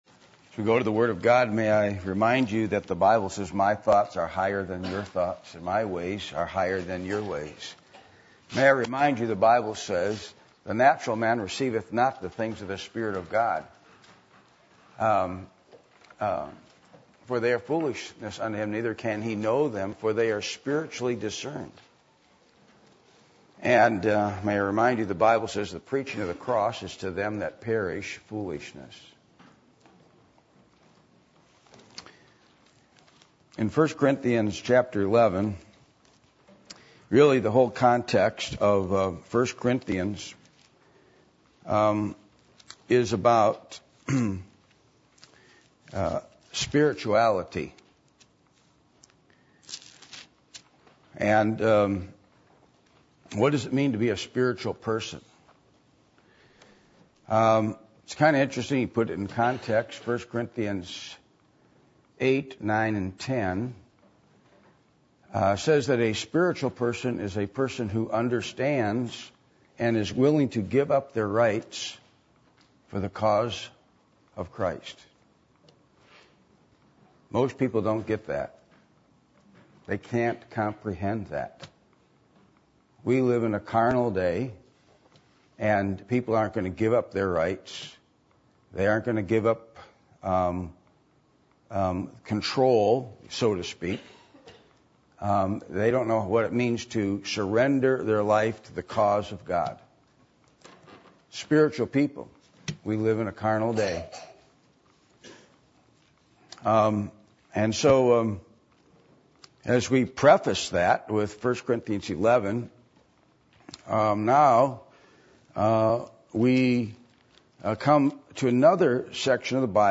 Passage: 1 Corinthians 11:3-15 Service Type: Sunday Evening